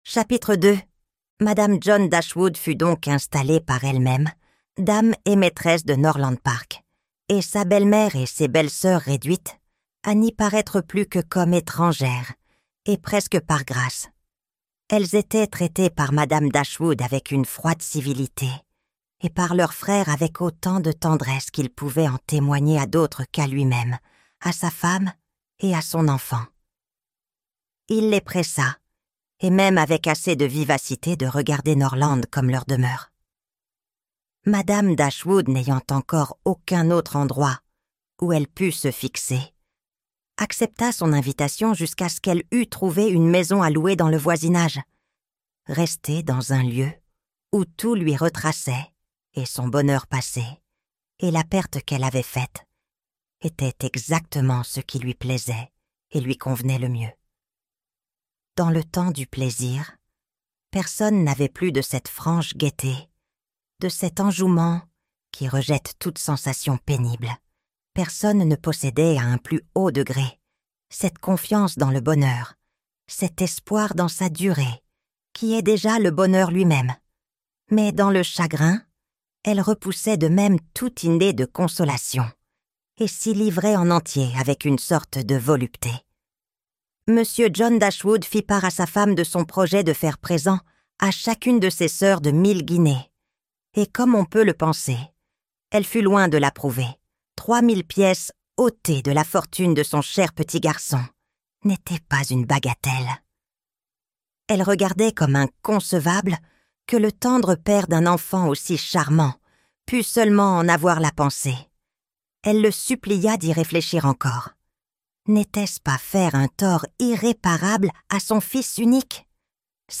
Raison et sentiments - Livre Audio